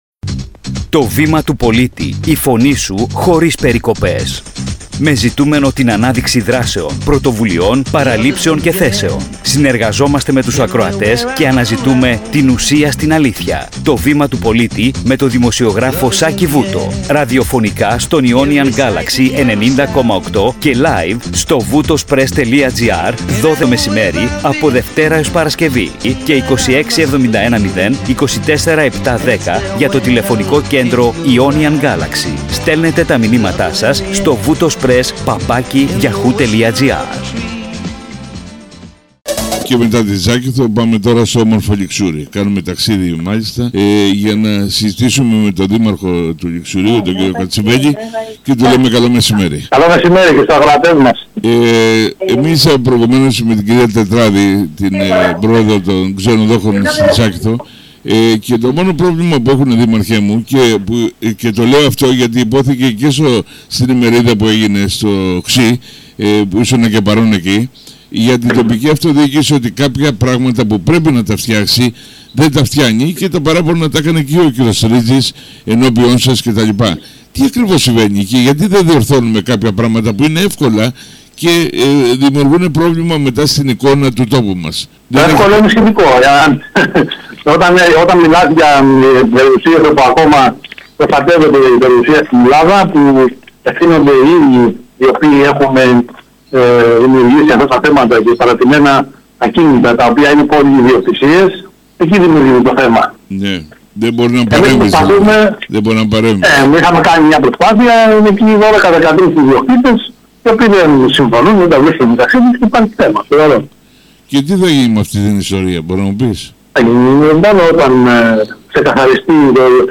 Στο «Βήμα του Πολίτη» στον Ionian Galaxy 90.8 φιλοξενήθηκε την Τετάρτη 31 Ιουλίου 2025 ο Δήμαρχος Ληξουρίου, Γιώργος Κατσιβέλης,